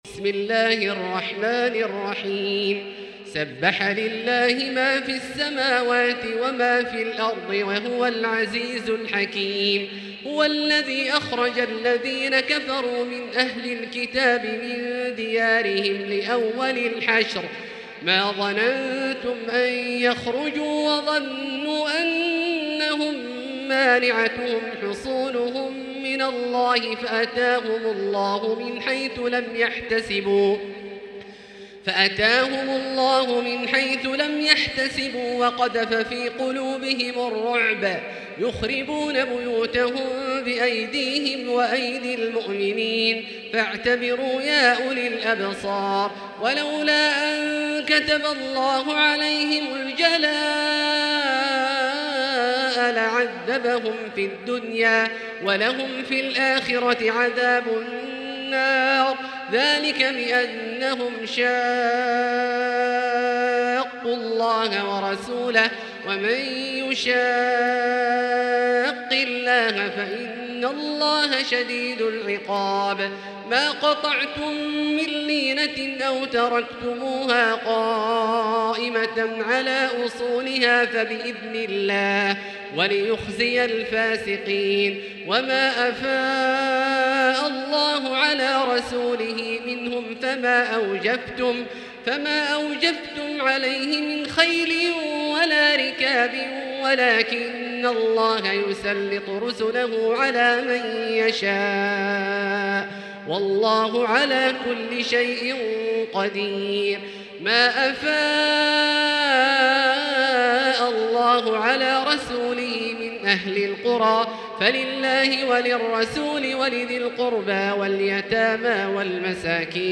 المكان: المسجد الحرام الشيخ: فضيلة الشيخ عبدالله الجهني فضيلة الشيخ عبدالله الجهني الحشر